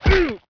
gen_die4.wav